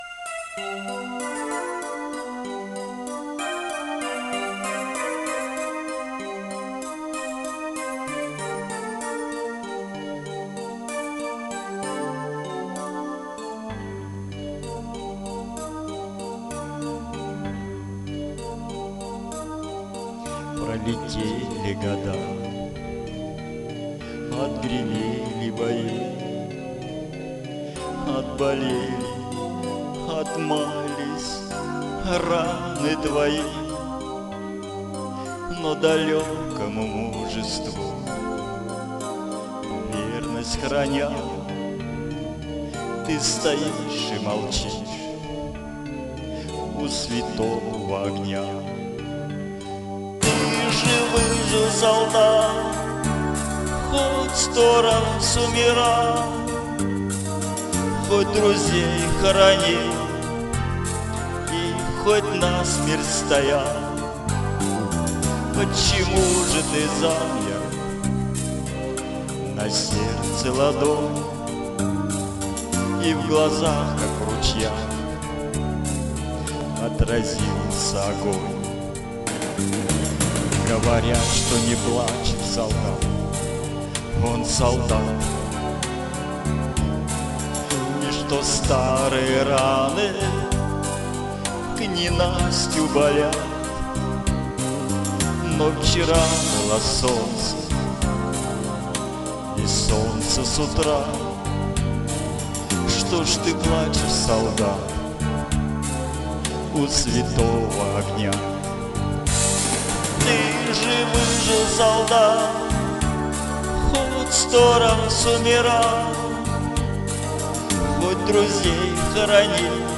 Военные и Патриотические